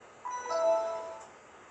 Windows vista log off
windows-vista-log-off.mp3